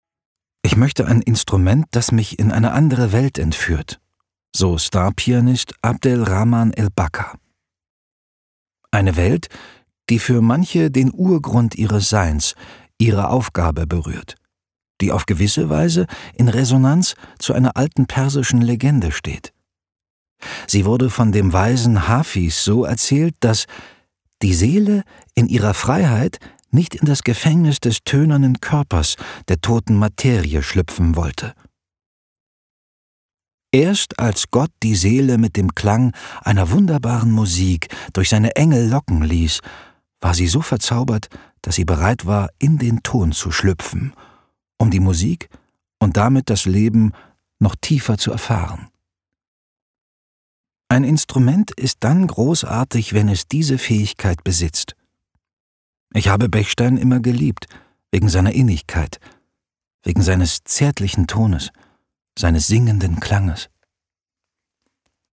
nuschelt eigentlich lieber. Aber wenn Text, Thema und Tantiemen stimmen, kriegt er auch schon mal die Zähne auseinander.